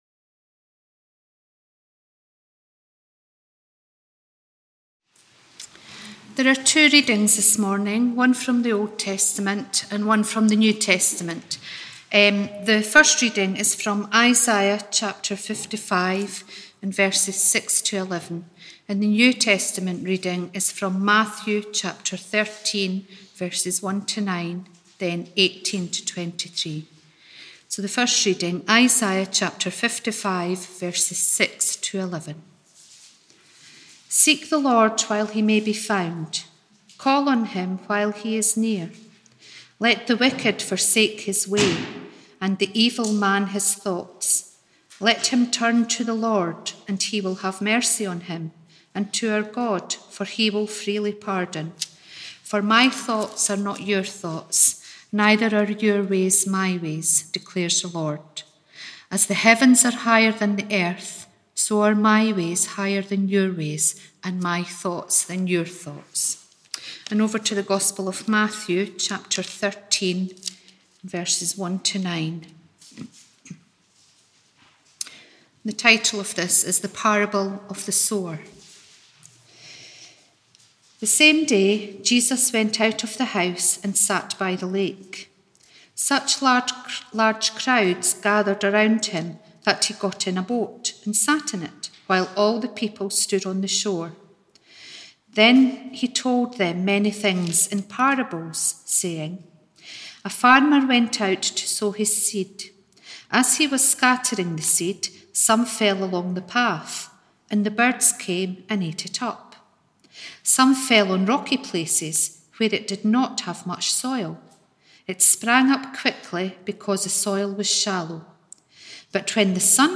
Morning Service 6th June 2021